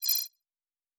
Sci-Fi Sounds / Interface